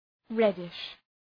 Shkrimi fonetik {‘redıʃ}